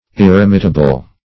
Meaning of irremittable. irremittable synonyms, pronunciation, spelling and more from Free Dictionary.